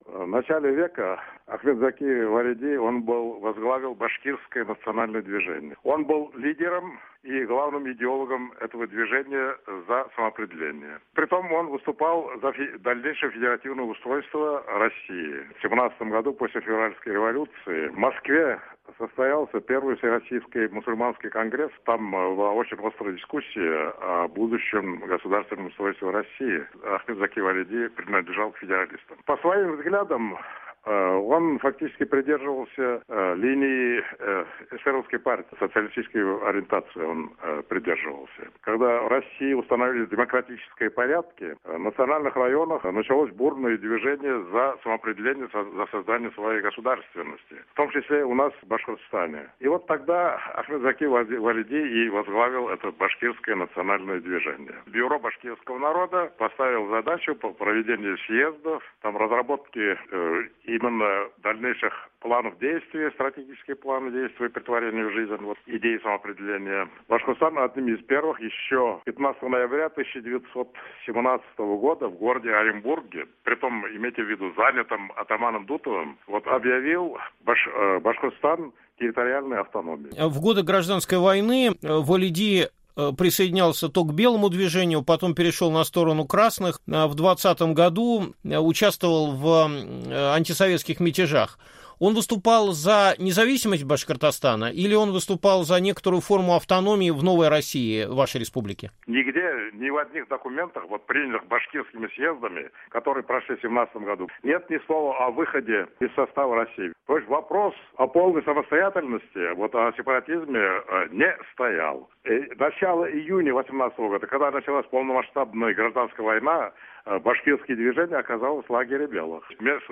Историк